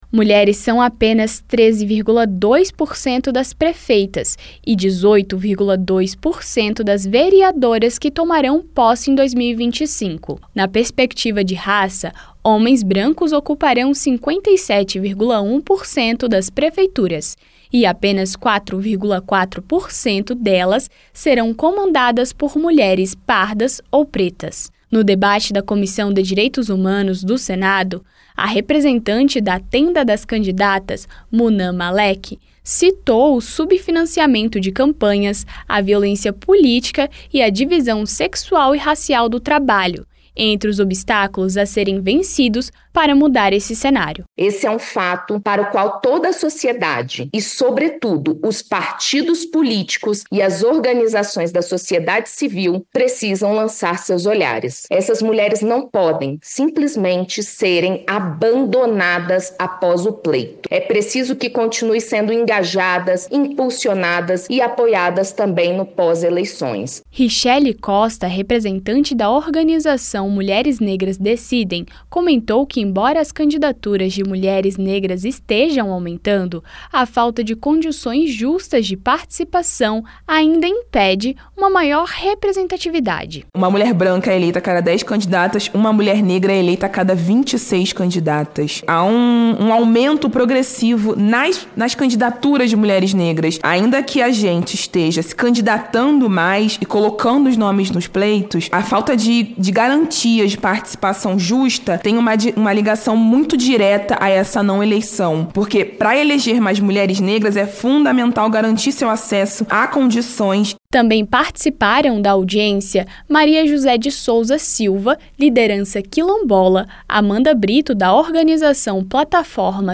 Rádio Senado
Audiência Pública